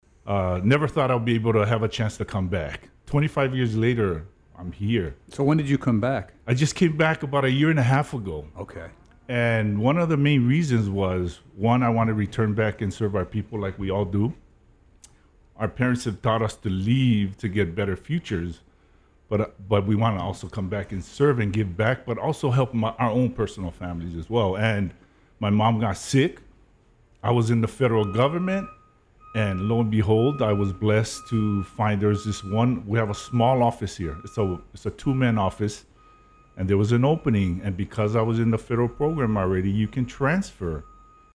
Ahead of the Federal Pathways Career fair that is happening today at the National Weather Service Compound, KHJ’s morning show had some federal employees in the studio to promote the event.